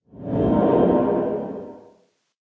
sounds / ambient / cave
cave12.ogg